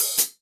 14HK OP.CL.wav